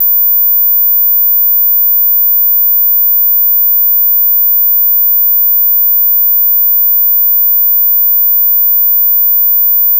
Bild 2: wavfilen ovan har körts genom ett program (Addistortion) som i detta fall adderar en asymmetrisk olinjäritet som skulle resulterat i en ren -30dB andraton på en sinus.